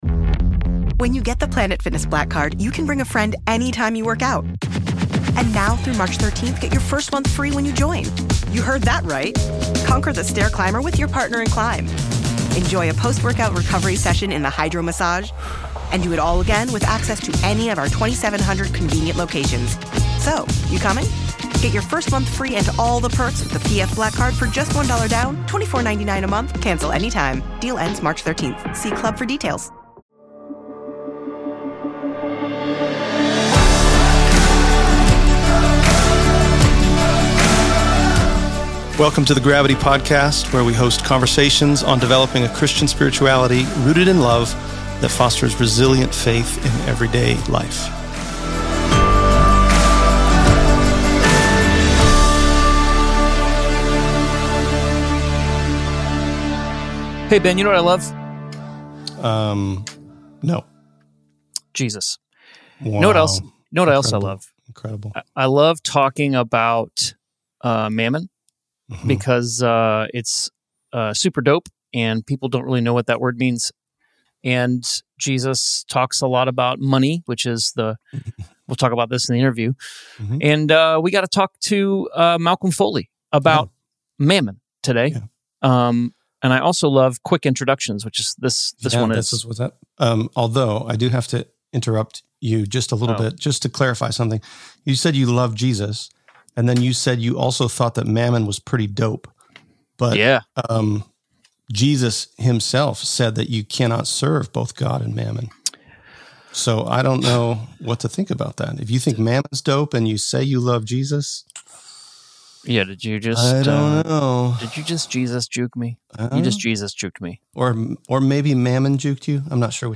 Conversations to help us develop a Christian spirituality rooted in love that fosters resilient faith in everyday life